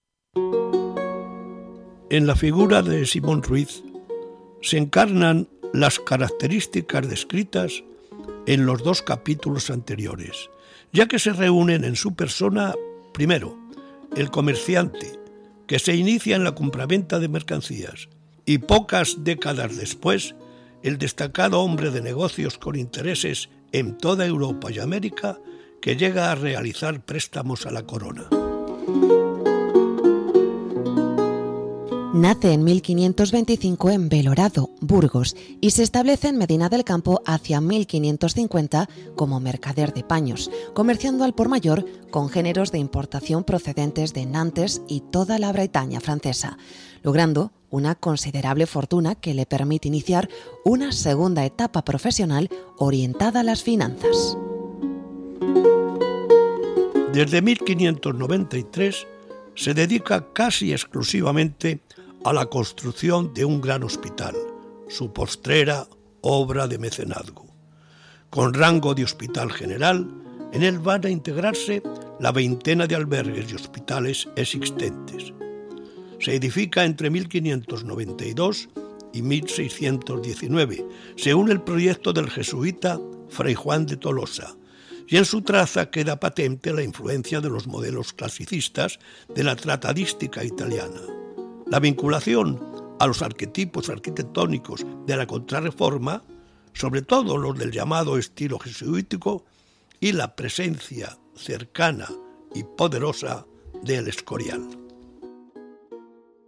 Locuciones generales de capítulos con documentos del Archivo Simón Ruiz